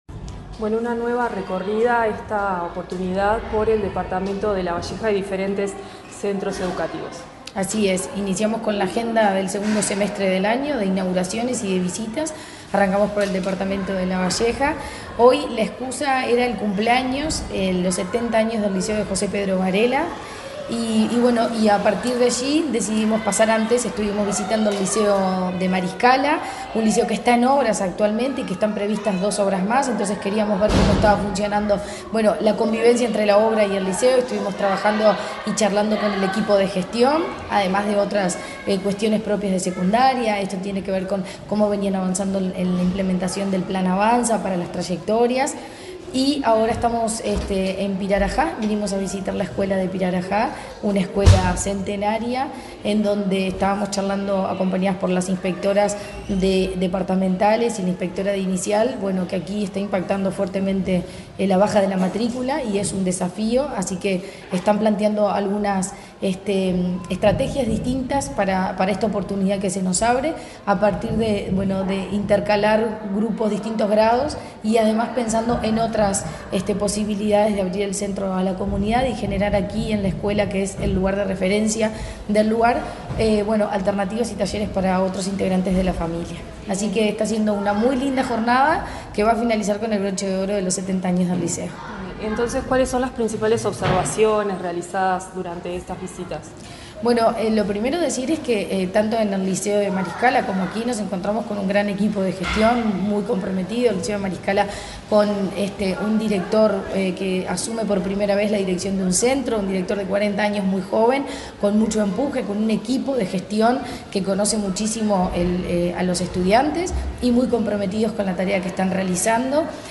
Entrevista a la presidenta de la ANEP, Virginia Cáceres
Entrevista a la presidenta de la ANEP, Virginia Cáceres 02/08/2024 Compartir Facebook X Copiar enlace WhatsApp LinkedIn La presidenta de la Administración Nacional de Educación Pública (ANEP), Virginia Cáceres, dialogó con Comunicación Presidencial en Lavalleja, donde visitó la escuela n.° 9 de Pirarajá y participó en el acto conmemorativo del 70.° aniversario del liceo de José Pedro Varela, entre otras actividades.